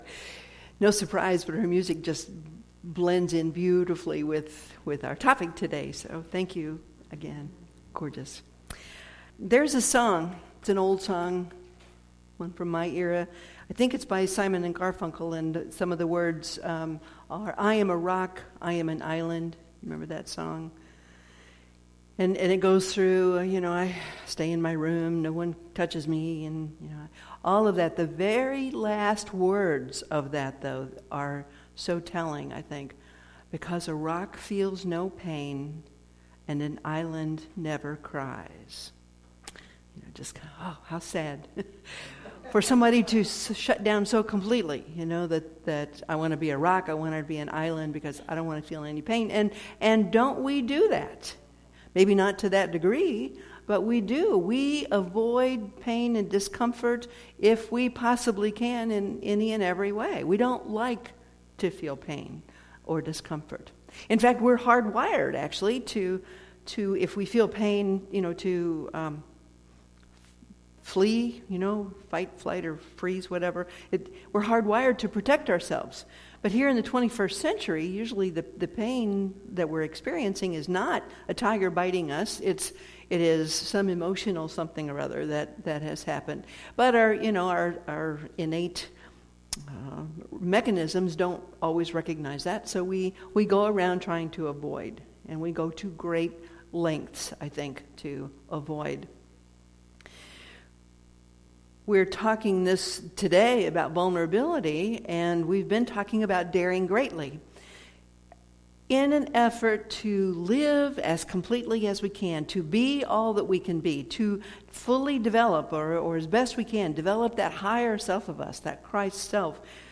Series: Sermons 2016